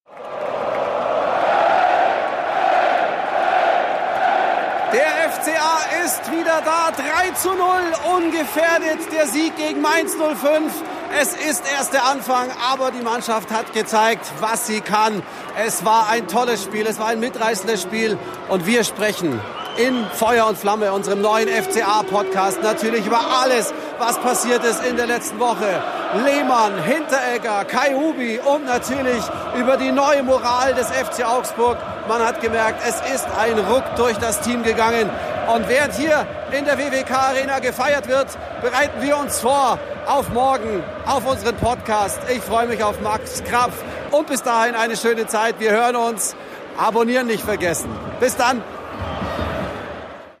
Stadion-Atmosphäre nach dem 3:0 gegen Mainz... und was Euch in der